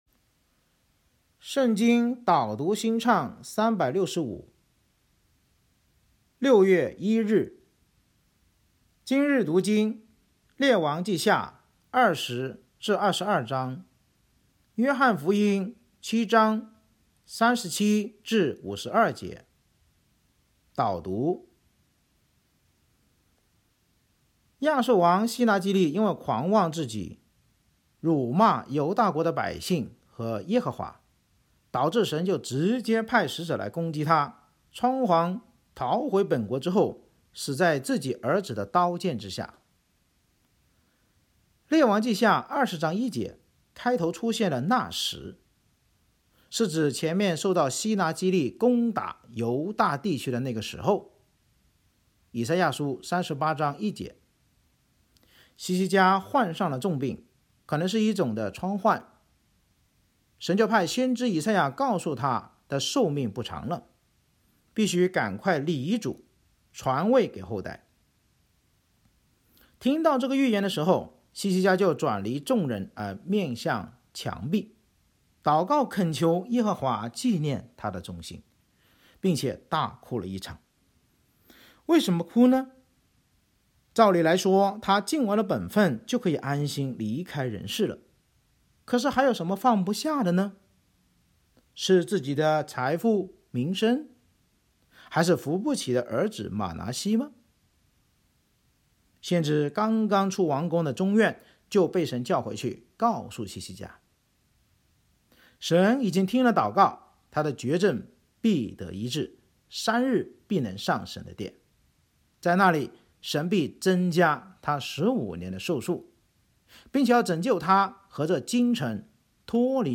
【导读新唱365】朗读6月1日.mp3